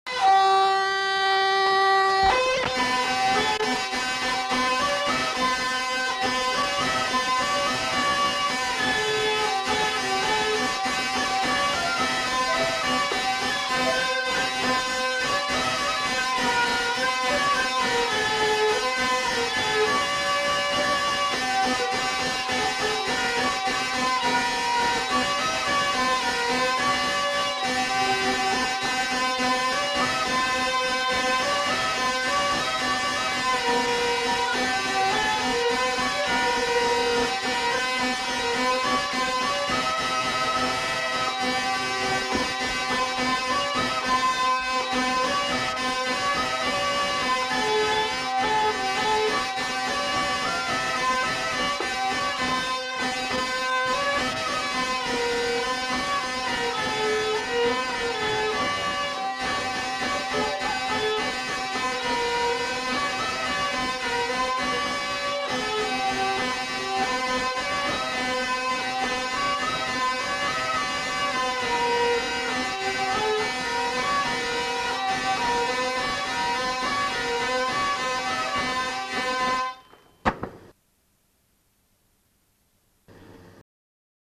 Aire culturelle : Gabardan
Lieu : Herré
Genre : morceau instrumental
Instrument de musique : vielle à roue ; accordéon diatonique
Danse : valse